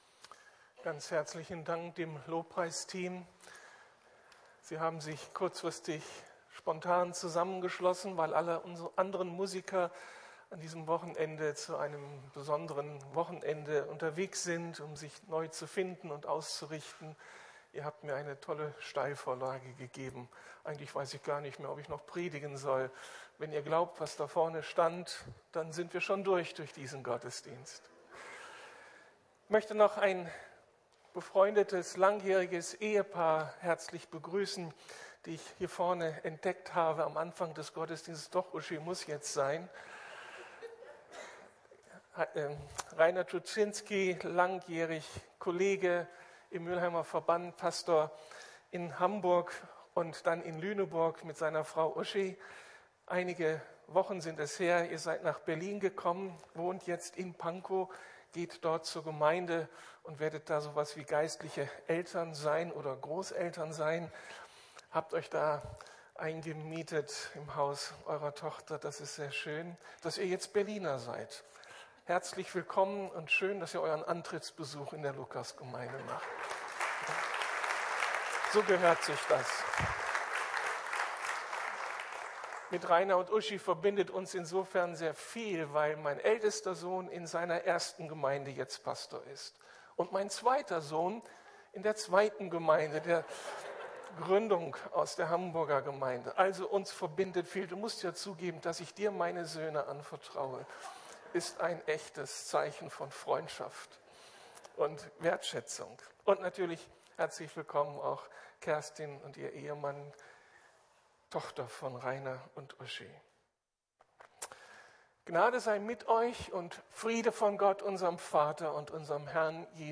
Solus Christus - Christus allein ~ Predigten der LUKAS GEMEINDE Podcast